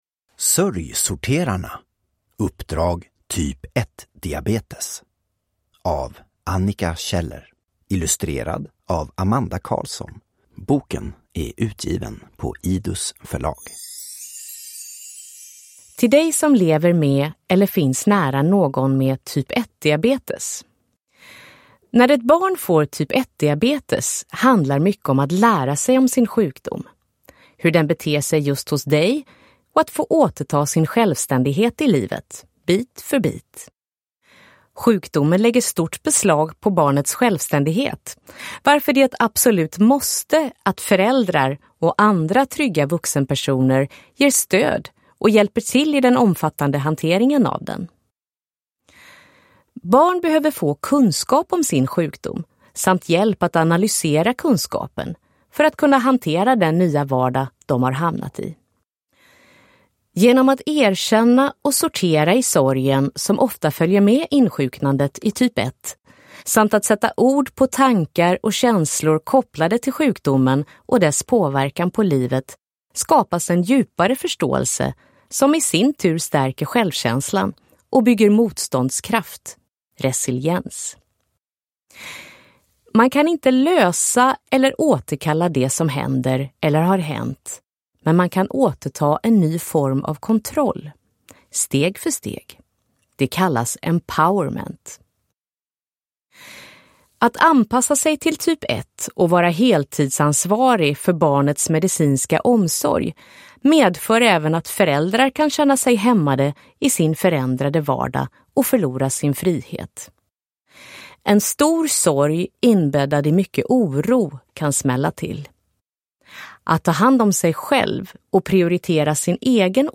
SÖRJsorterarna - uppdrag typ 1-diabetes (ljudbok) av Annika Kjeller